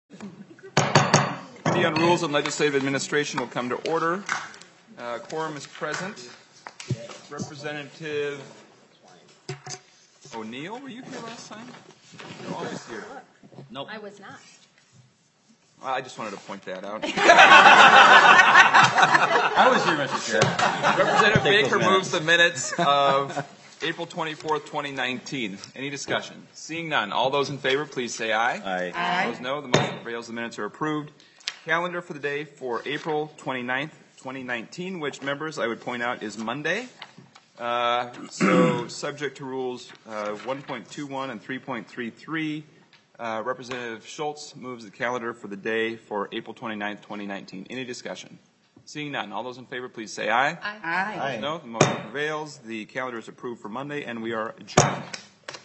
Chair: Rep. Ryan Winkler